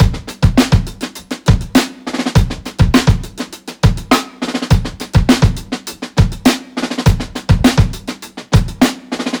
• 102 Bpm Drum Loop G Key.wav
Free breakbeat - kick tuned to the G note. Loudest frequency: 998Hz
102-bpm-drum-loop-g-key-zFk.wav